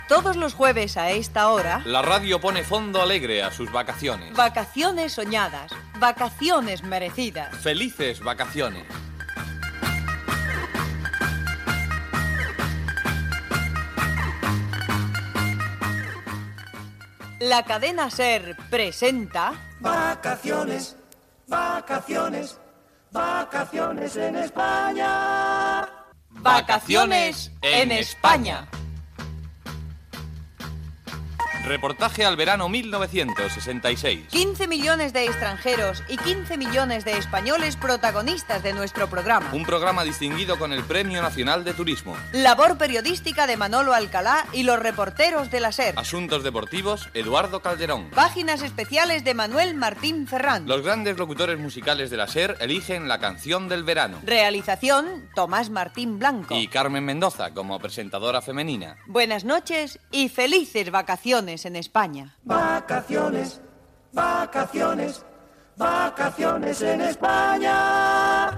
Careta del programa amb els noms de l'equip.